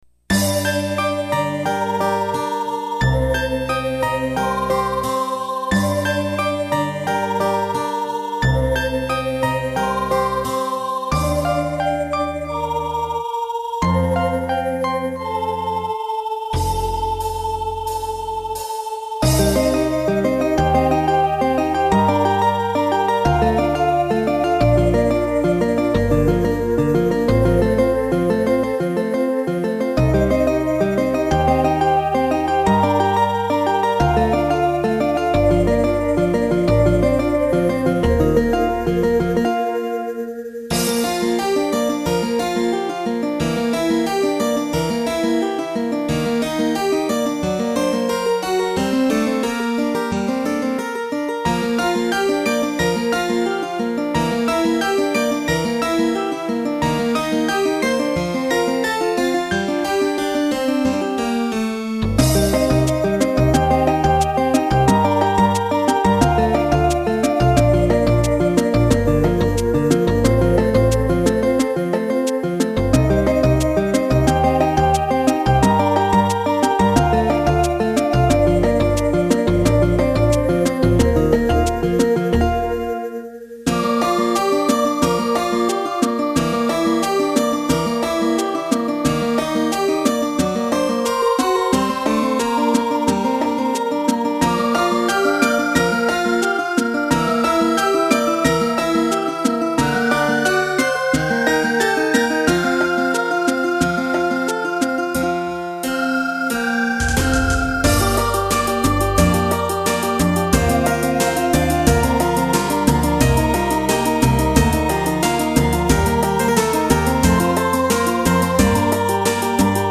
色鮮やかな桜の景観とともに「ここまで来たか」とこれまでの冒険を思わせる素敵な曲。
音色の響きからオルゴールやらを使うもどうもしっくりこないのでエコーでごまかす方向にシフトするといい感じになりました。
曲の始まりのシンバルロールのためだけにガクダンを使うのはもったいないのでちょっと無茶っぽくなりました。